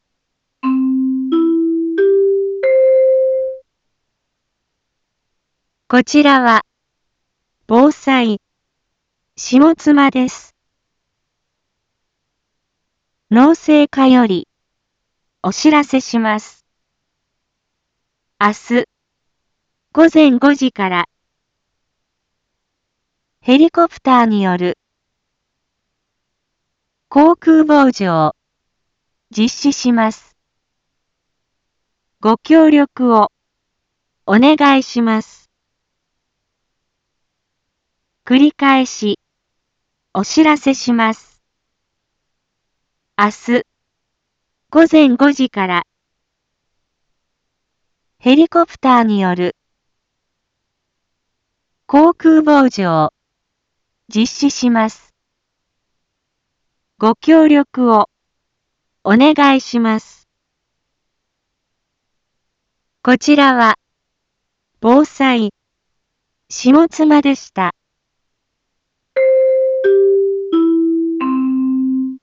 一般放送情報
Back Home 一般放送情報 音声放送 再生 一般放送情報 登録日時：2022-07-24 18:01:15 タイトル：農林航空防除について インフォメーション：こちらは、防災、下妻です。